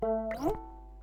Custom notification sounds
Because they were meant to be played on phones alone, I was not too concerned with audio quality besides hopefully avoiding weird artifacts or unwanted high-ish pitch noise that are especially noticable through a phone's tiny speaker(s).
These were recorded ad hoc after work, using my first ukulele (miguel almeria pure series, concert) with low-g tuning with having a "sometimes quiet office environment" in mind (so that it's audible, while also not being too abrasive to distract others).
I wish I wrote down or remembered what notes I slide between, but I think it was A3 to A4 (i.e. from the 2nd to the 14th fret on the G-string).